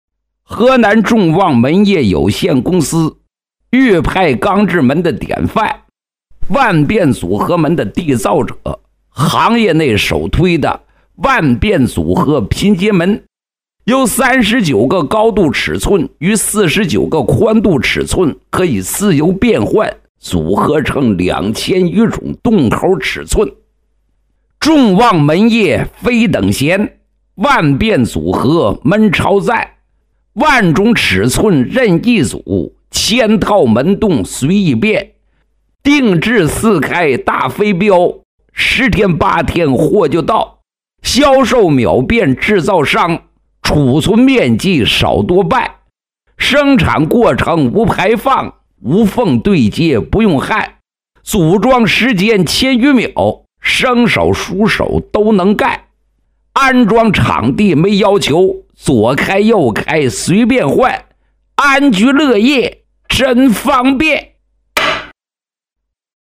男6 国语 男声 河南众旺门业有限公司 模仿单田芳 沉稳|娓娓道来